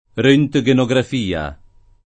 rHjgenograf&a; meno bene, alla ted.,